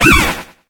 Cri de Pomdrapi dans Pokémon HOME.